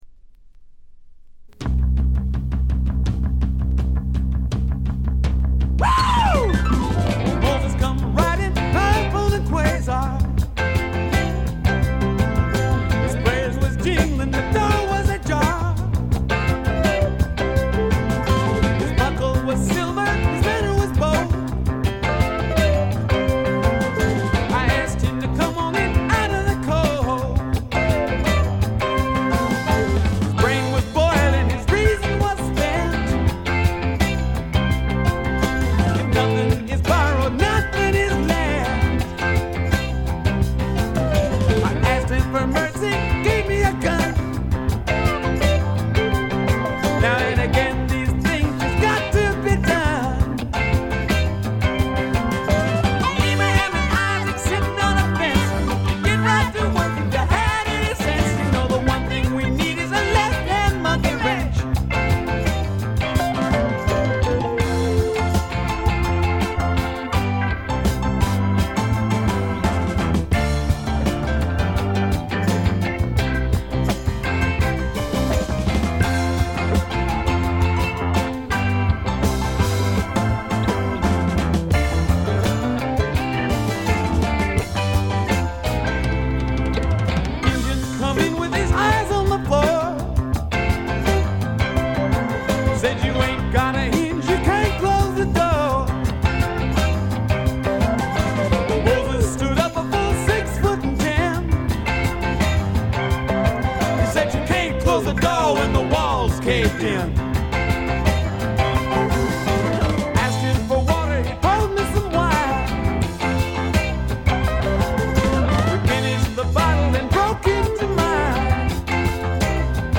部分試聴ですが、微細なチリプチ程度でほとんどノイズ感無し。
試聴曲は現品からの取り込み音源です。